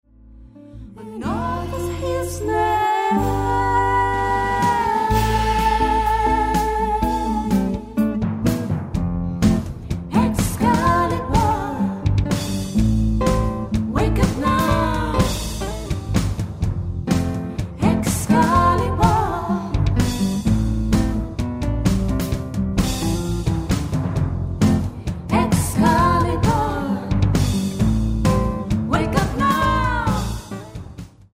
guitars
voices